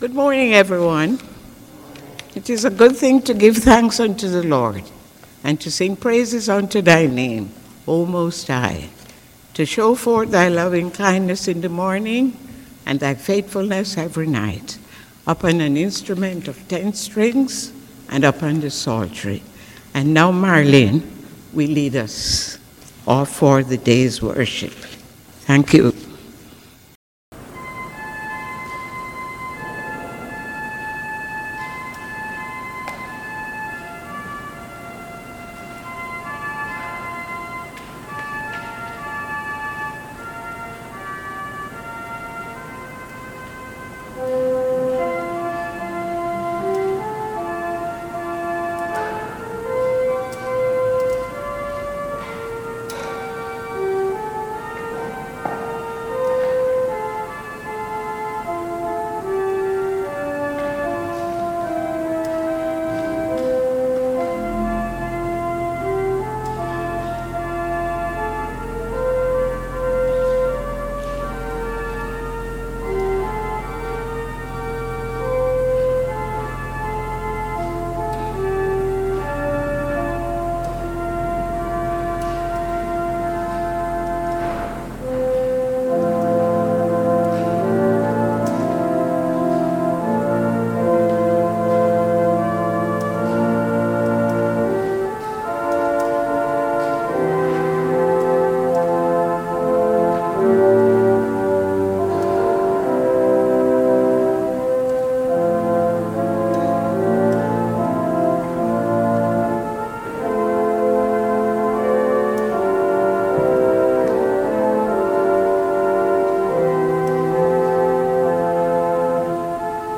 Worship Service December 5, 2021 | First Baptist Church, Malden, Massachusetts
Prelude
Video Presentation (audio only)